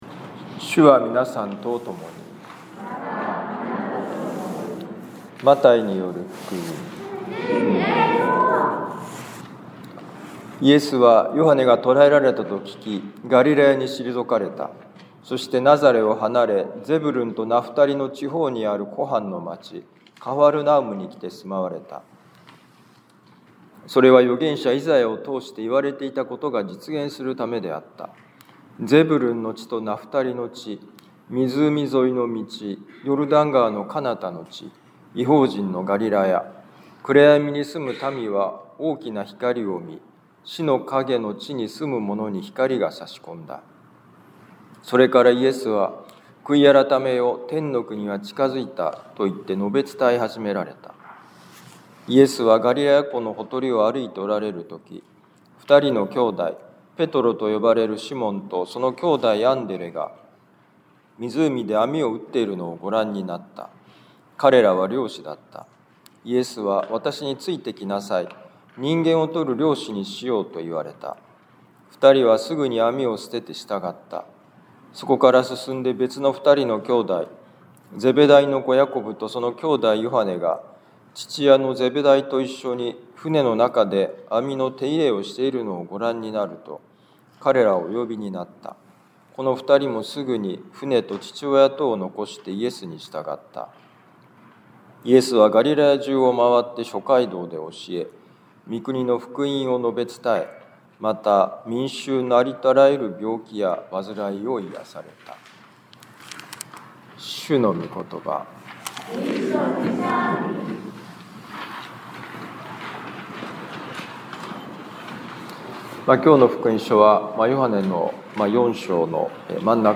マタイ福音書4章12-23節「従った理由」2026年1月25日年間第３主日防府カトリック教会